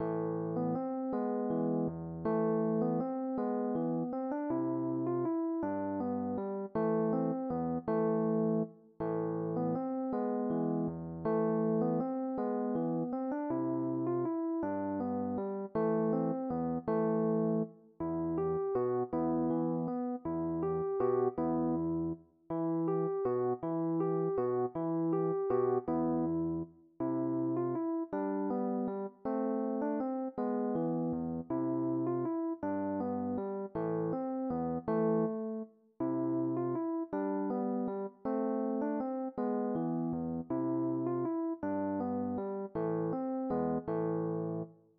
Nicht ohne Bewegung zu singen